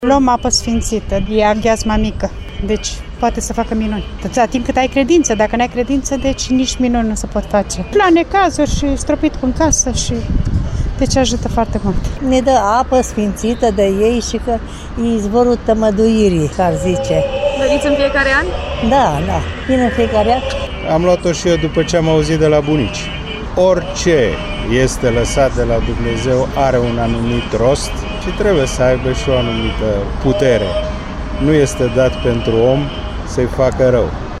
La Sfânta Liturghie a praznicului Maicii Domnului, oficiată vineri la Catedrala Mitropolitană din Timişoara au participat peste o mie de credincioşi.
Tradiţia spune că apa sfinţită în această zi, îi fereşte de boli pe cei care o beau… cu acest gând în minte, timişorenii au luat parte la liturghie dar şi cu credinţă:
credinciosi.mp3